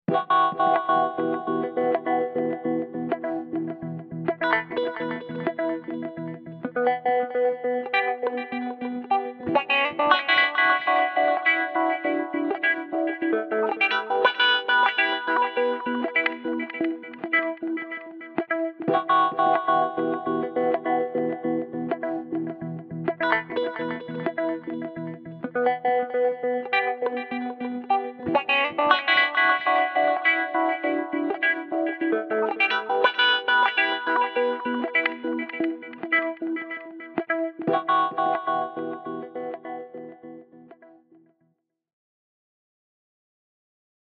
podXT-autowahchopper-v1.mp3